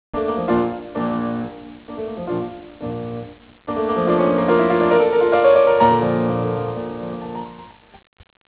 中期のソナタではほとんど全ての主題は上昇するデザインを持っている。